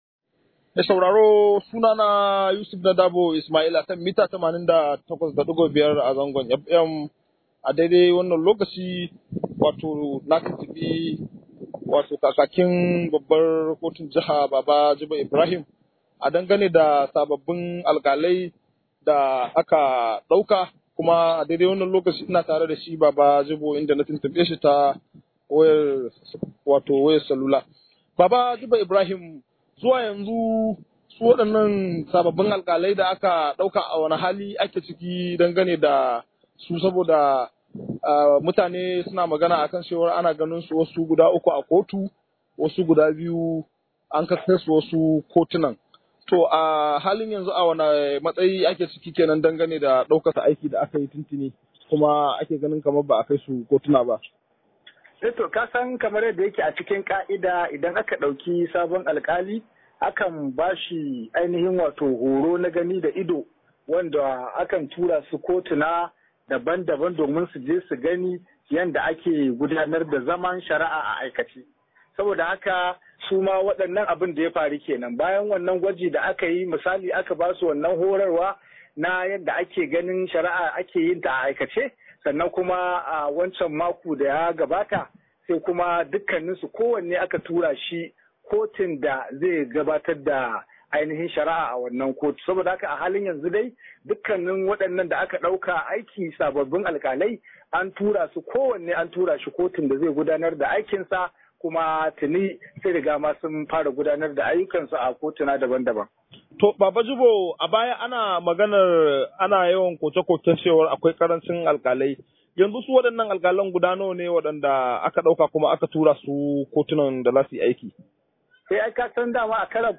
Rahoto